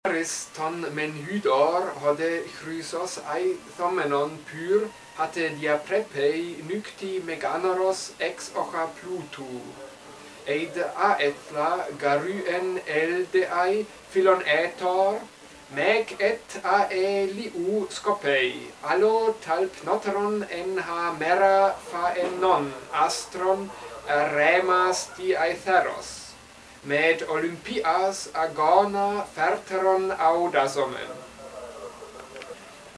Pronunciation Listen to the text spoken by 2 different Speakers: Speaker 1 Speaker 2